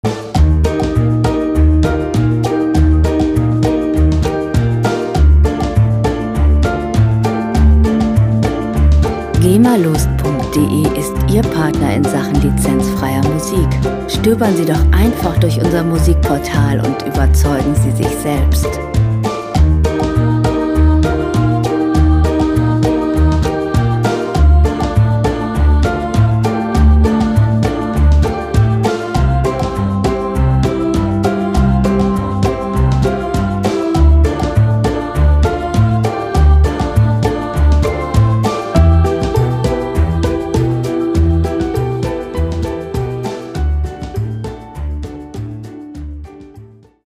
• Zirkusmusik